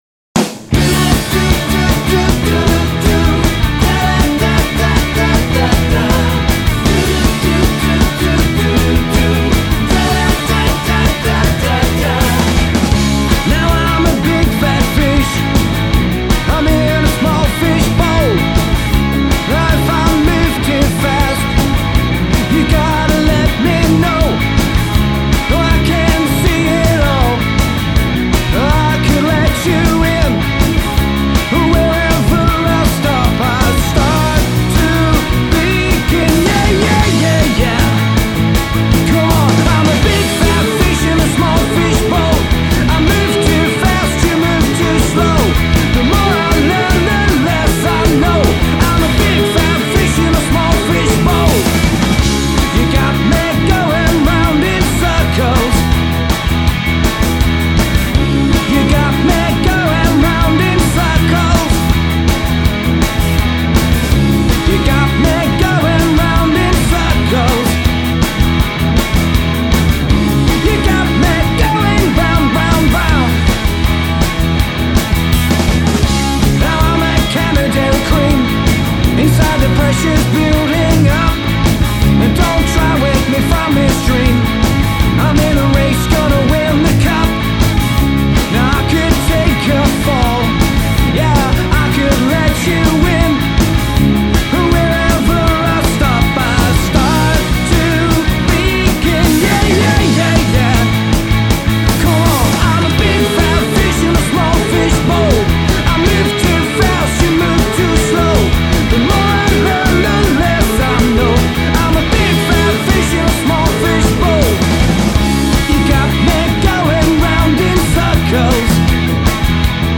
Rock/Pop